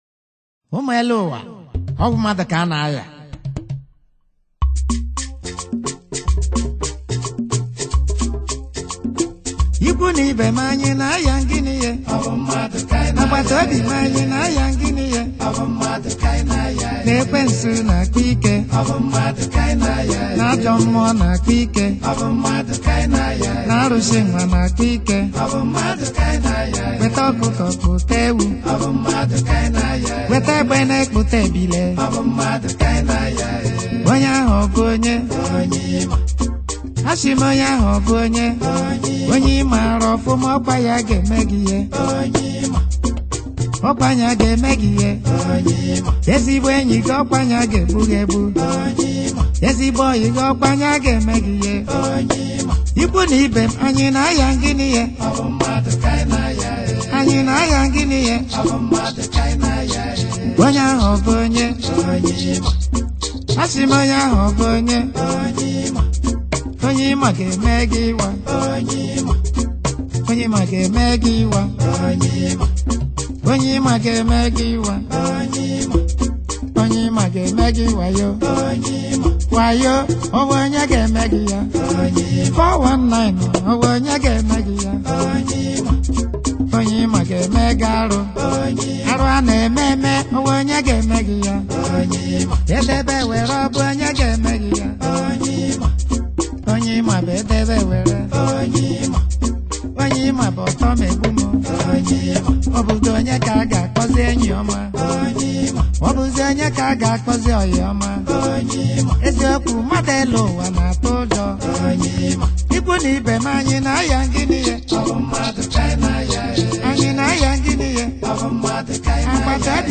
highlife track
a good highlife tune